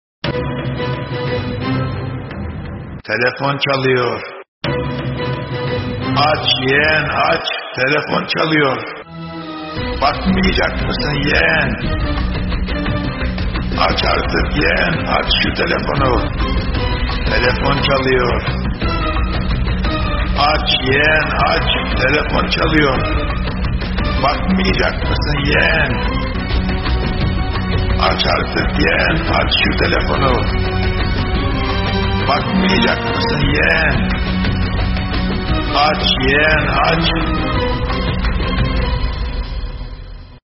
Kategori: Zil Sesleri